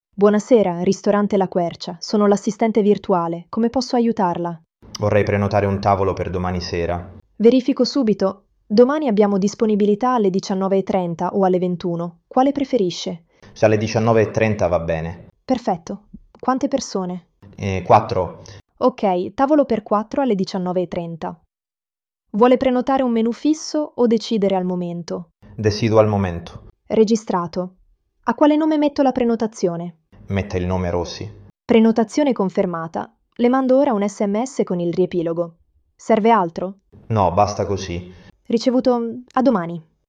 Risponde con voce naturale e tono umano, senza suoni robotici.